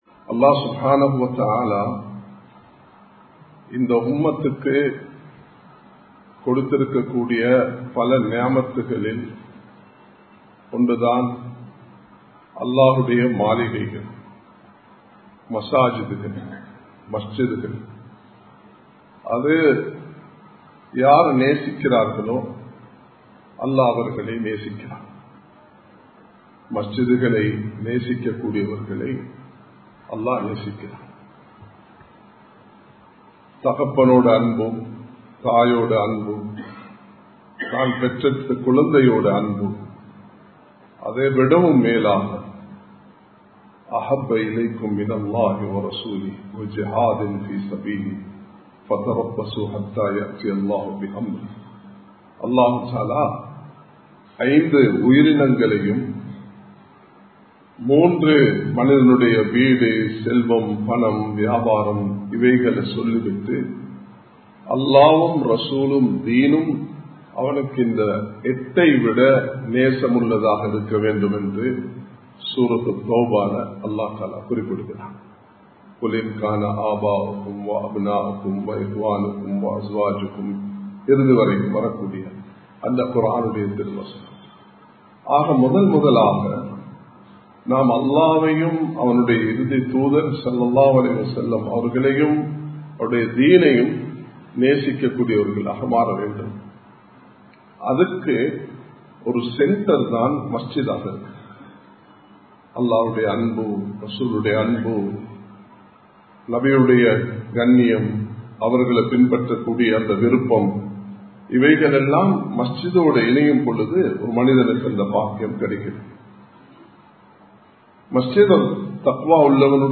ஹஜ் & மஸ்ஜிதின் அவசியம் | Audio Bayans | All Ceylon Muslim Youth Community | Addalaichenai
Samman Kottu Jumua Masjith (Red Masjith)